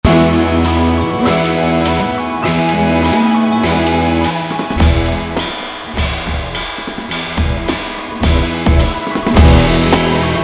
And that trashy china-esq crash cymbal that keeps coming in and out (at double duration each time — eighth notes, then quarter, half, whole, breve) sits perfectly in the mix.
…it sounds like junk by itself!